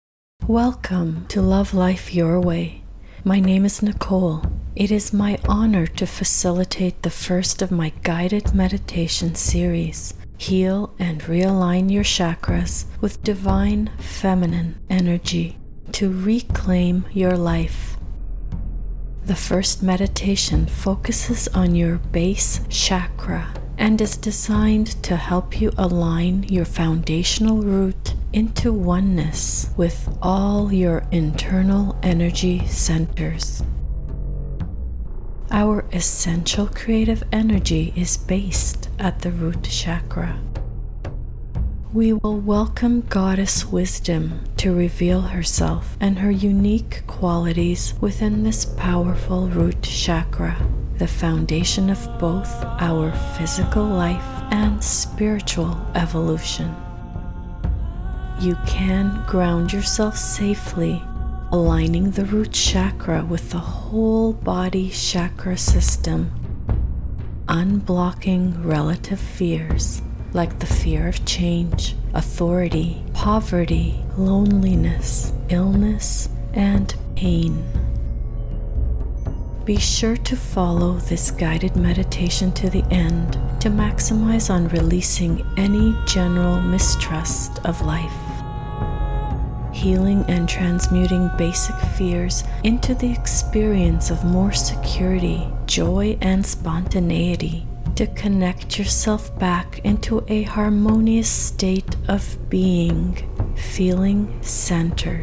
33-minute guided meditation audio (MP3) to Heal Your Root Chakra with Divine Feminine Energy.